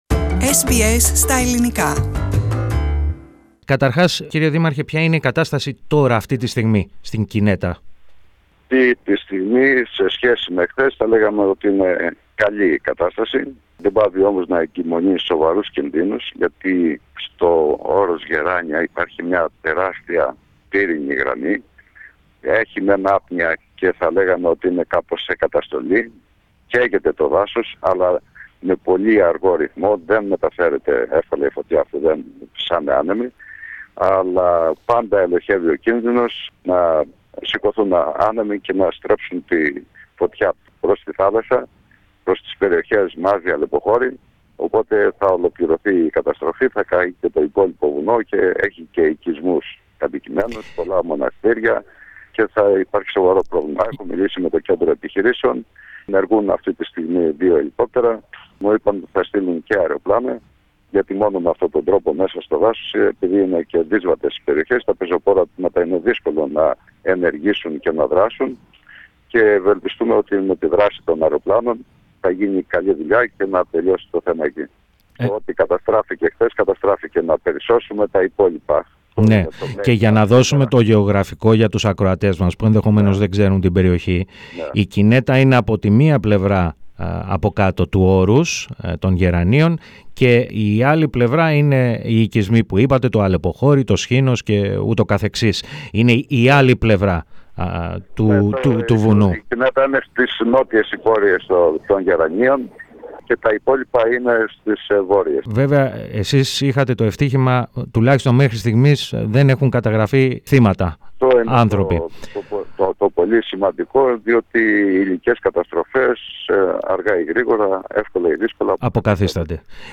Ο δήμαρχος Μεγαρέων, Γρηγόρης Σταμούλης, μίλησε στο πρόγραμμα μας για την κατάσταση, που επικρατούσε, σήμερα το πρωί, στην ευρύτερη περιοχή της Κινέτας, μετά το πέρασμα της πύρινης λαίλαπας.